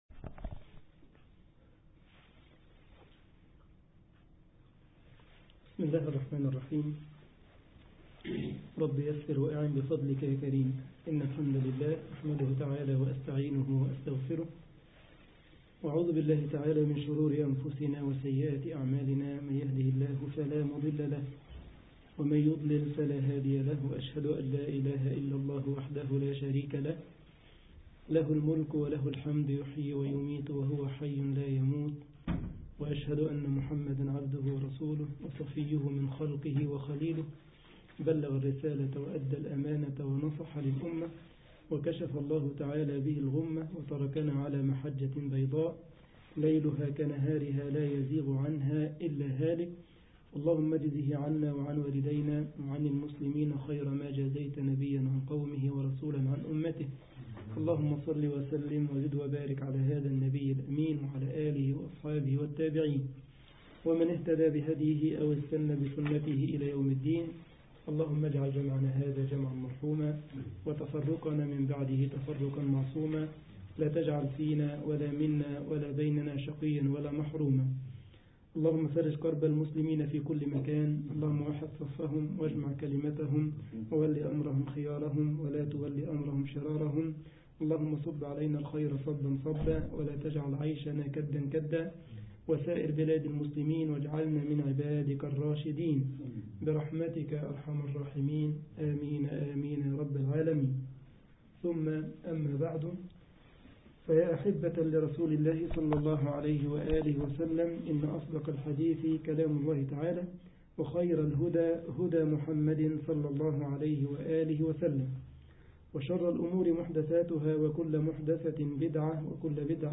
صحيح القصص النبوي 23 ـ قصة نبي الله يونس عليه السلام طباعة البريد الإلكتروني التفاصيل كتب بواسطة: admin المجموعة: صحيح القصص النبوي Download مصلى جامعة السارلند ـ ألمانيا التفاصيل نشر بتاريخ: الثلاثاء، 05 شباط/فبراير 2013 21:57 الزيارات: 2041 التالي